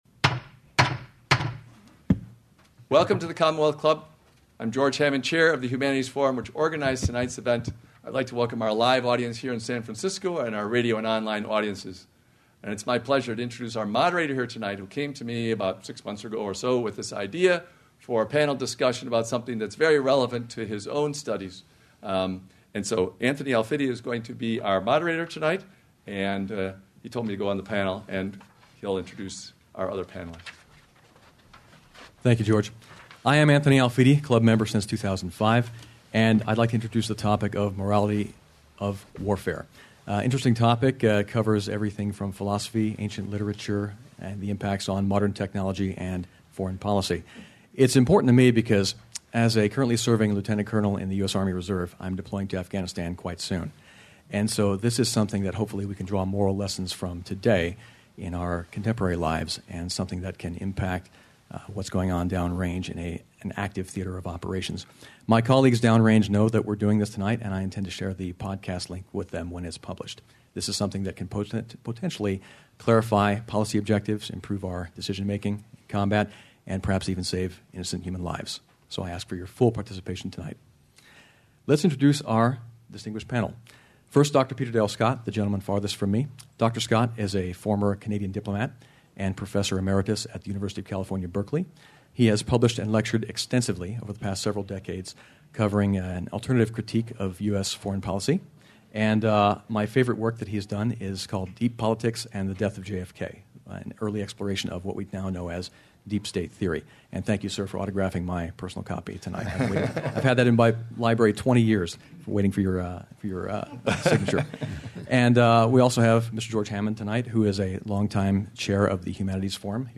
Lecture Audio
Can our daily life moral or ethical schemes be extended to restrain this most violent and destructive part of our lives? Our panel will discuss that in the 21st century context of drone wars, non-state actors, collateral damage, heightened